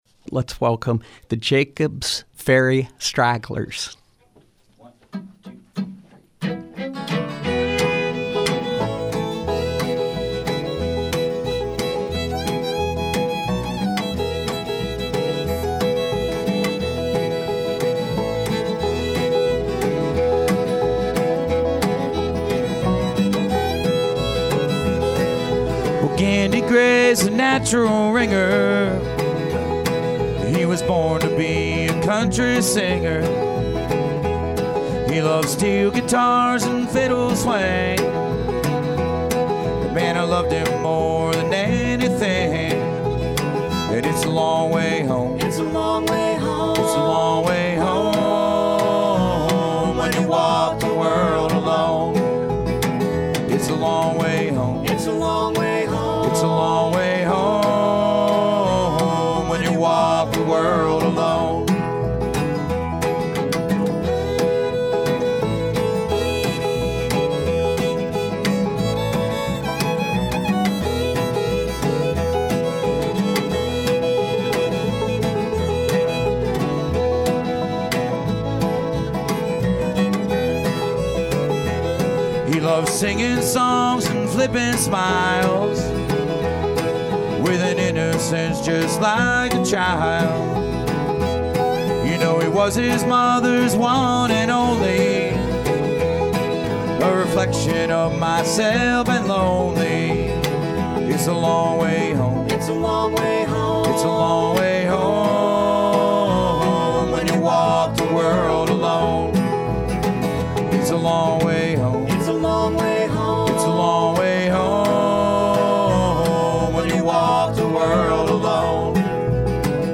Appalachian bluegrass
guitar, vocals
fiddle, vocals
clawhammer banjo
upright bass, vocals